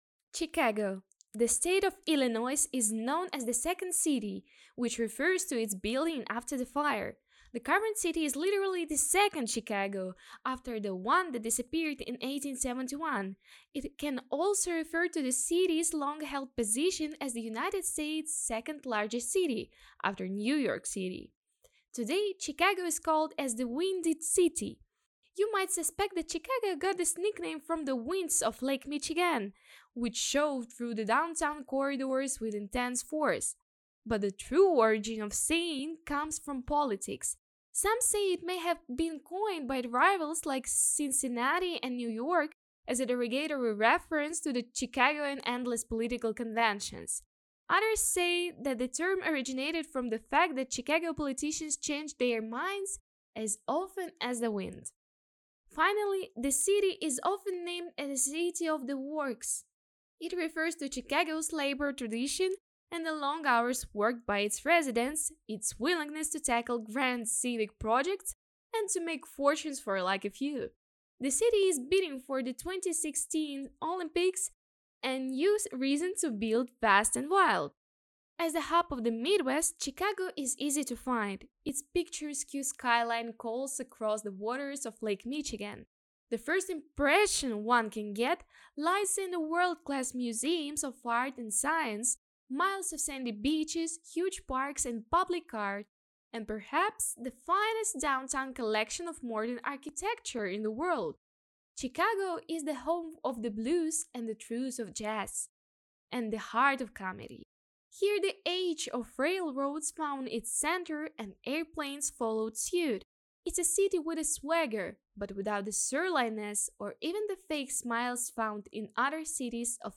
Жен, Закадровый текст
Студийный микрофон FiFine AM8